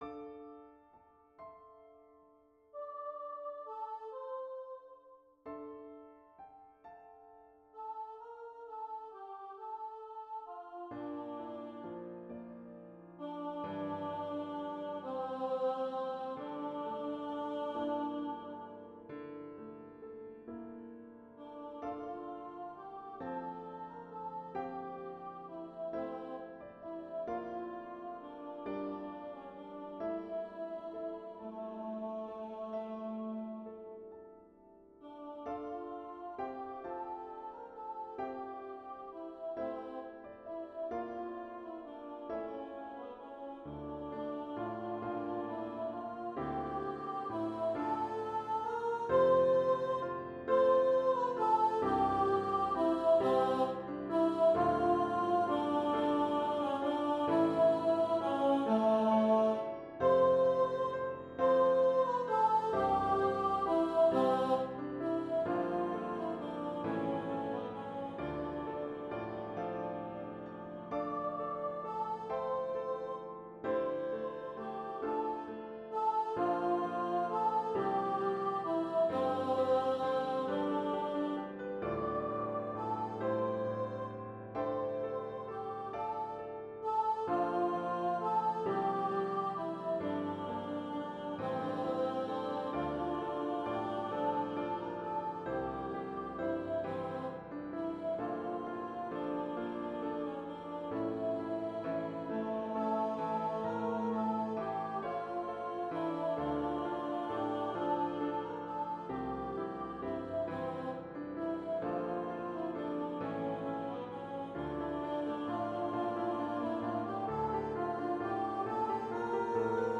Soprano Duet and Piano